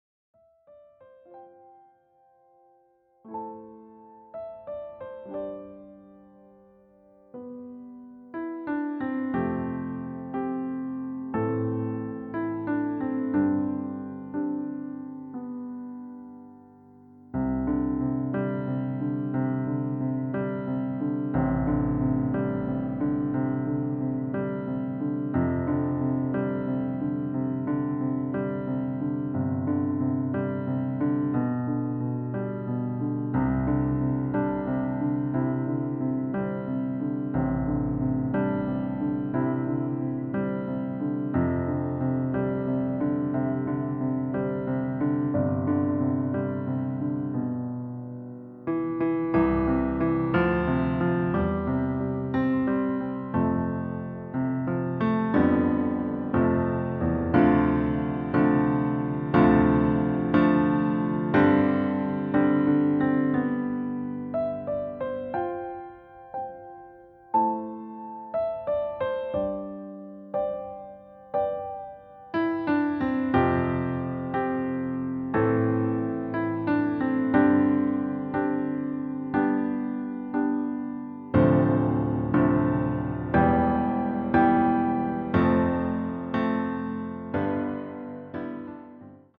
• Tonart: C, Db, Eb, G
• Art: Flügelversion
• Das Instrumental beinhaltet NICHT die Leadstimme
Klavier / Streicher